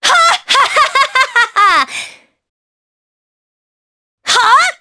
Naila-vox-get_jp.wav